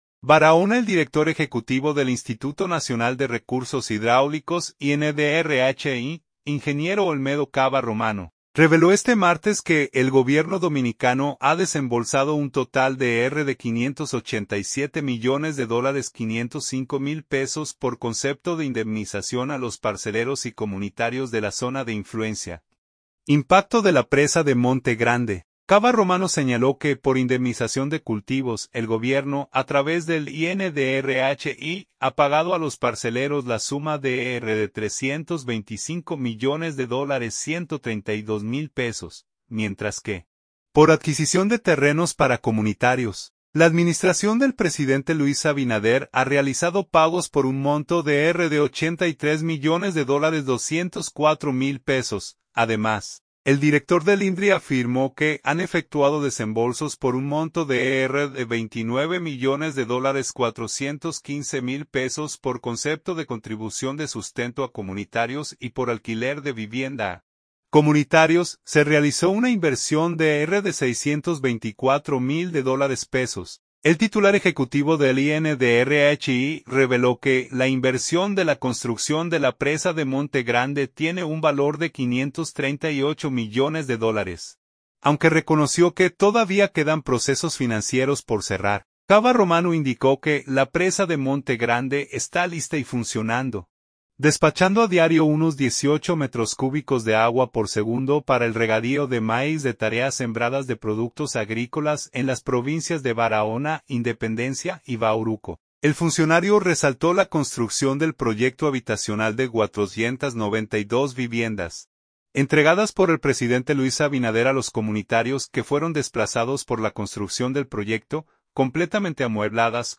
El director INDRHI fue entrevistado por el staff del programa "Hoy Mismo", desde la cortina de la Presa de Monte Grande.